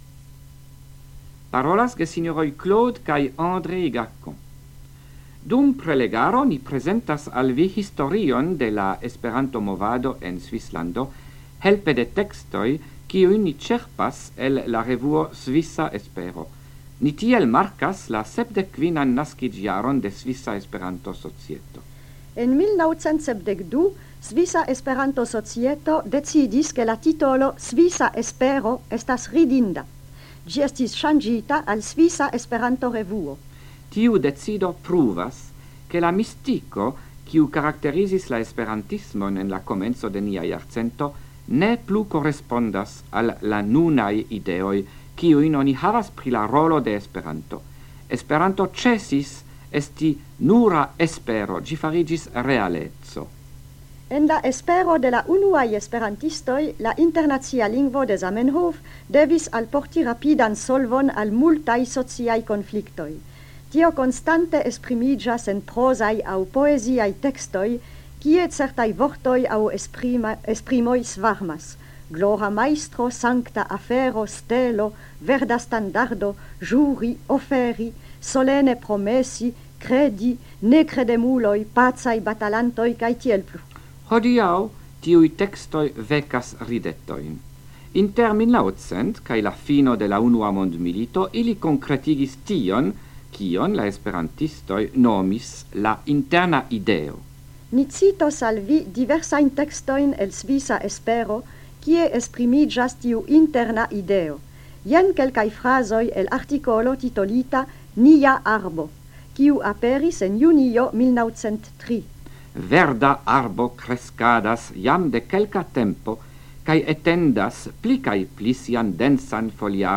Radioprelegoj en la jaro 1978